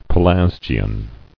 [Pe·las·gi·an]